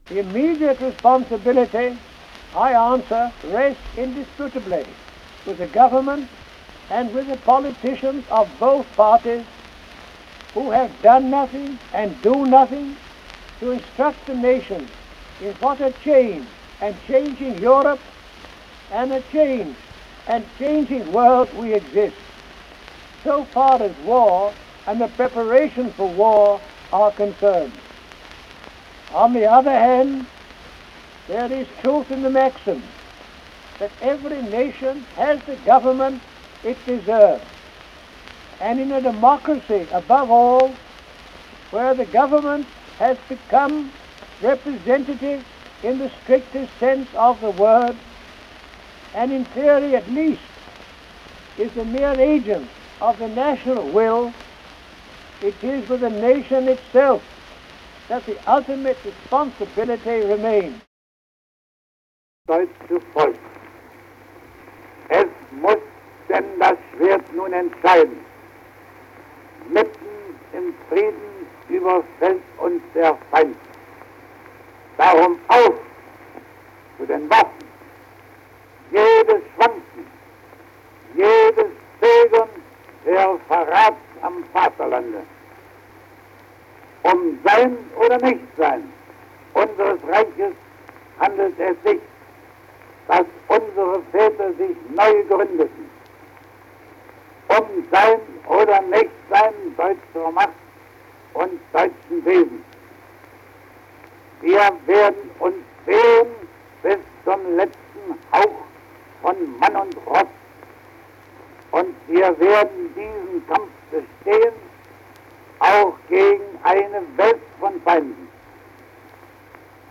Here, by way of a compilation done by two colleagues in 1998, is a collection of some of the important voices associated with that war.
voices-of-world-war-1.mp3